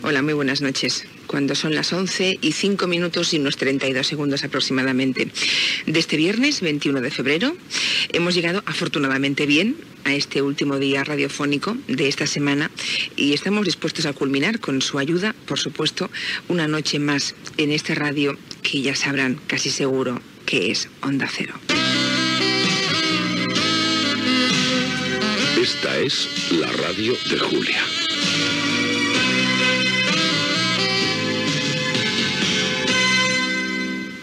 Salutació a l'inici del programa, indicatiu del programa
Entreteniment